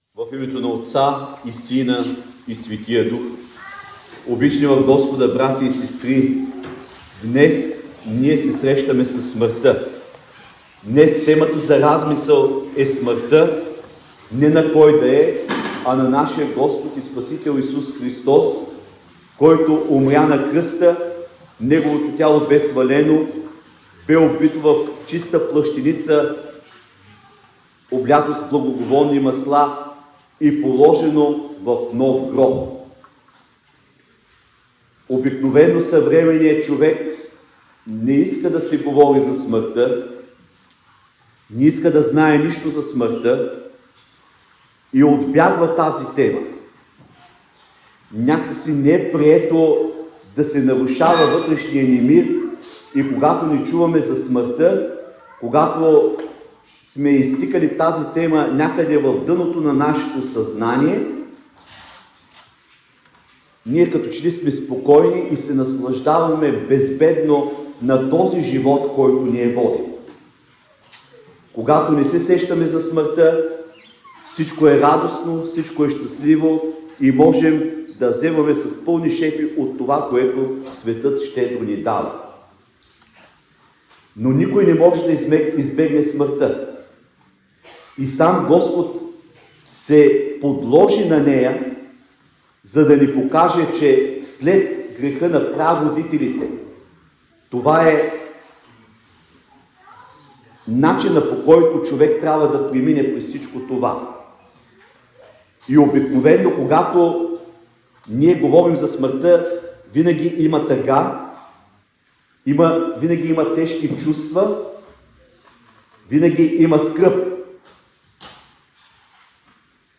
Велики петък (вечерта – Опело Христово) – 2025 г.
Неделни проповеди